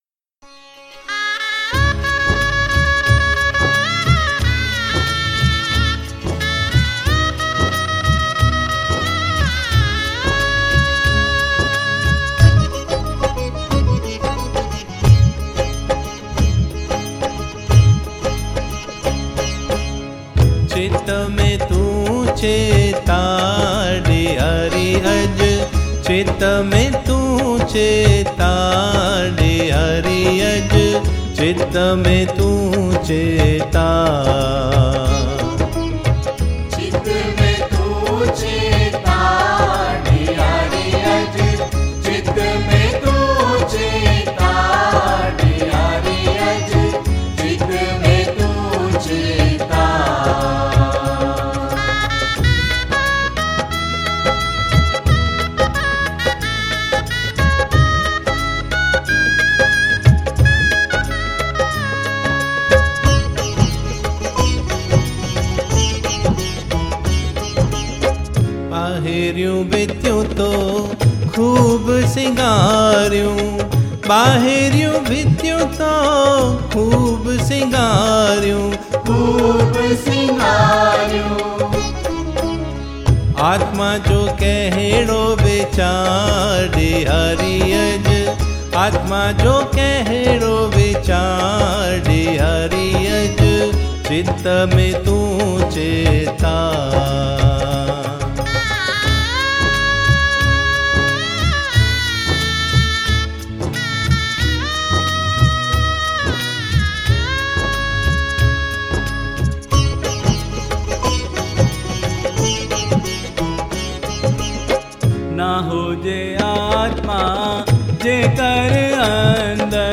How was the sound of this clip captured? Recorded at: Line-In Studio